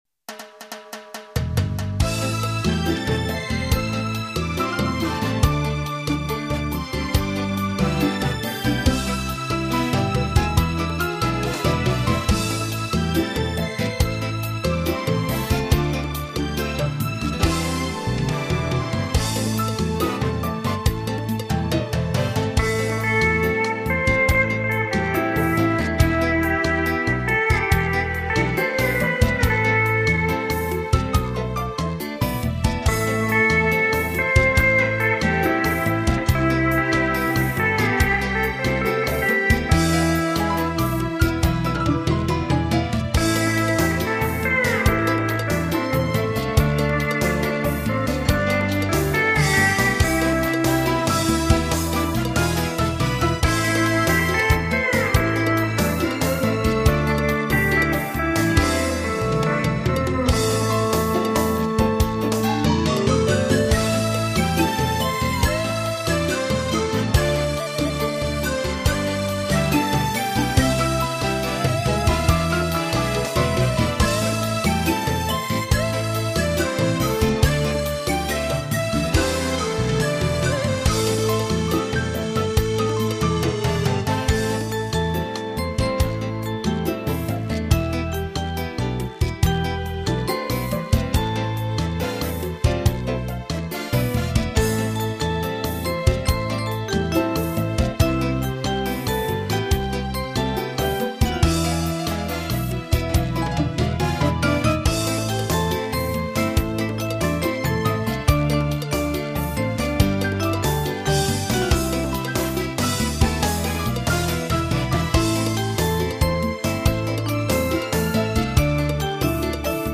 伦巴